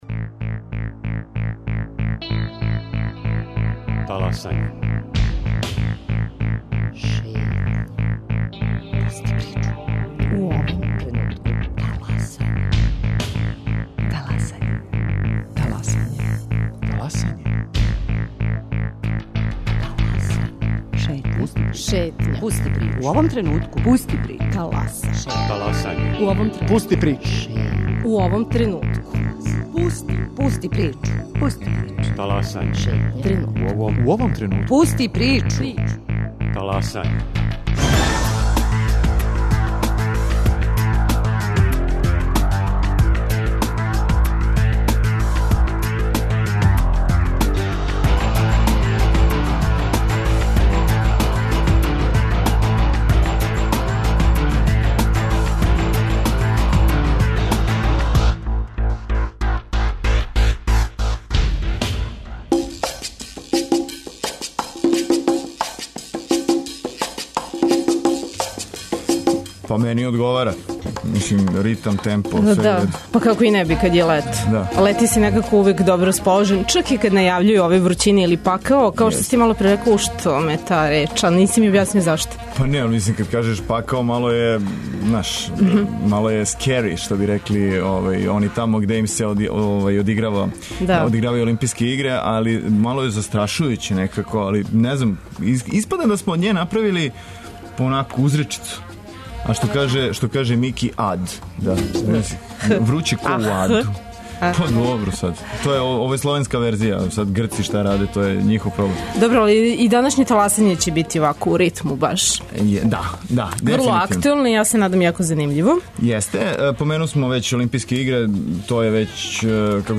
Преносе је репортери Радио Београда 1 са лица места.